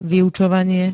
Zvukové nahrávky niektorých slov
ehif-vyucovanie.spx